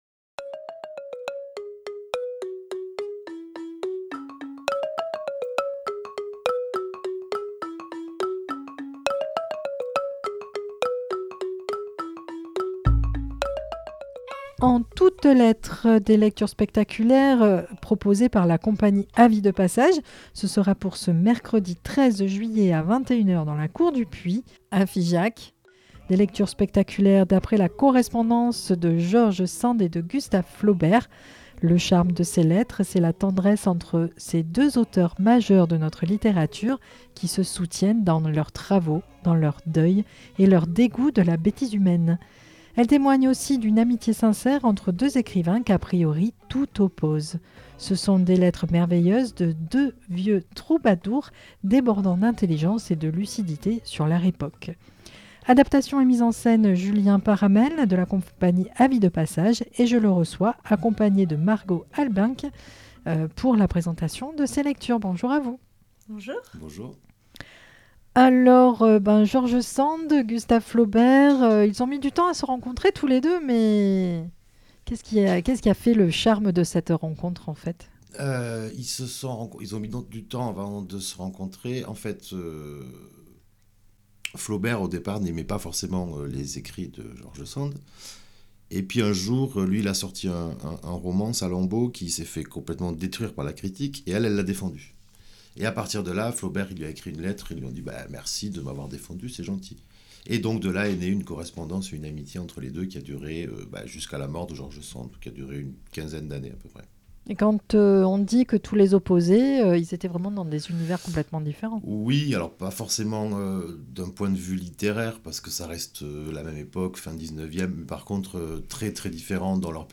télécharger l'émission Lecture spectaculaire d'après la correspondance de Georges Sand et Gustave Flaubert. Le charme de ces lettres, c'est la tendresse entre ces deux auteurs majeurs de notre littérature qui se soutiennent dans leurs travaux, dans leurs deuils et leur dégoût de la bêtise humaine !